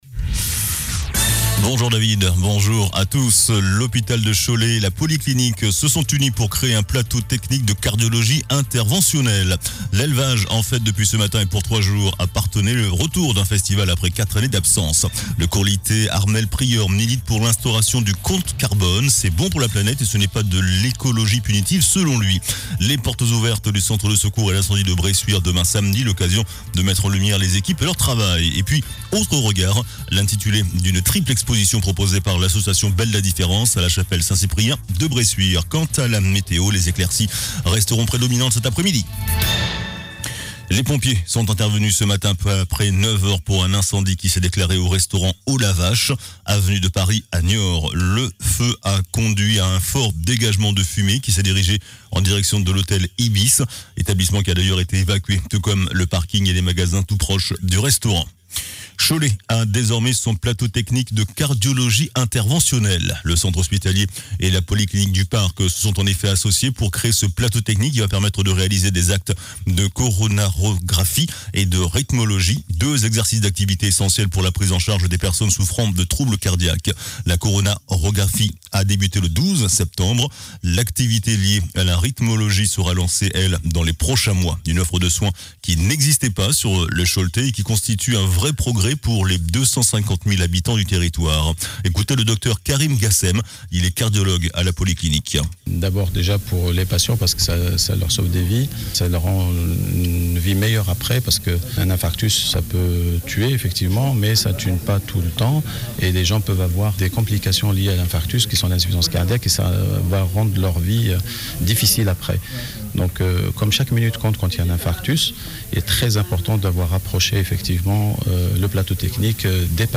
JOURNAL DU VENDREDI 22 SEPTEMBRE ( MIDI )